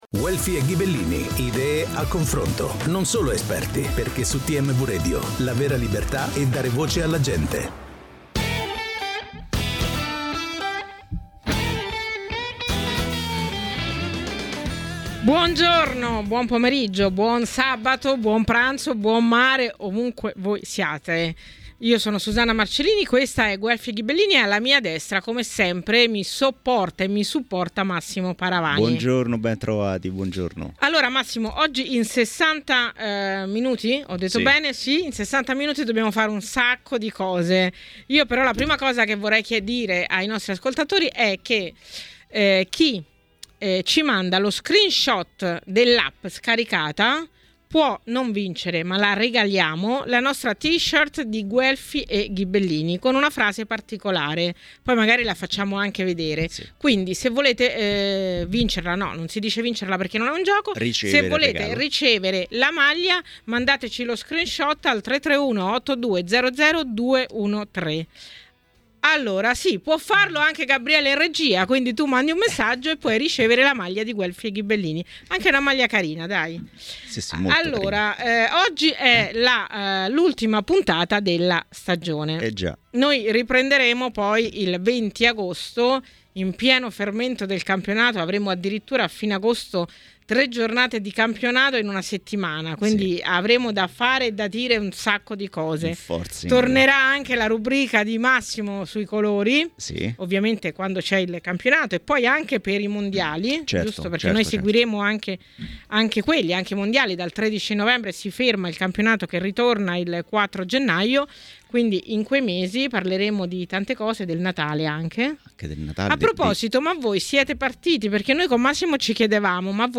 è intervenuto ai microfoni di TMW Radio nel corso di Guelfi e Ghibellini